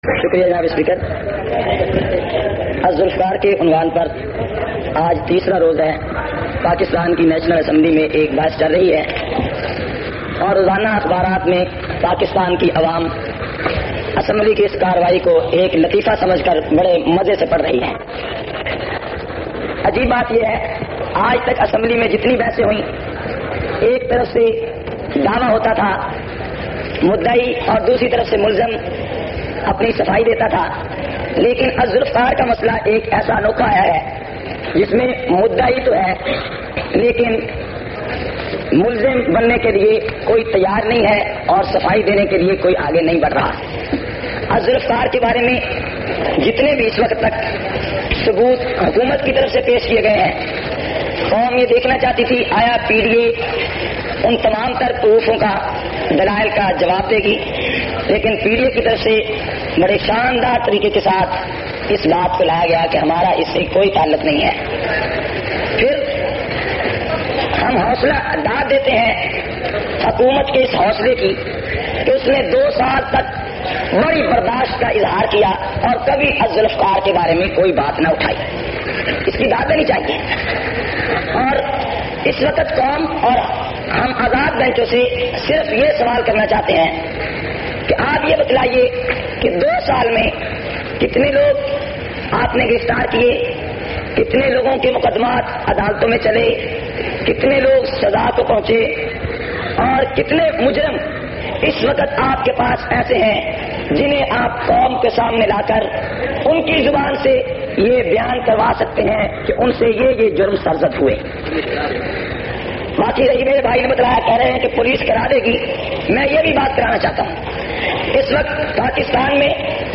455- Qaomi Assembly Khutbat Vol 9.mp3